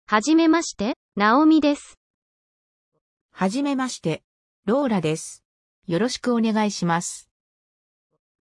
商用利用OKの音声読み上げソフト「音読さん」の機能と使い方 | ワードプレステーマTCD
会話形式
音読さんの会話機能は、2種類以上の声で会話形式の音声を読み上げてくれます。
kaiwa.mp3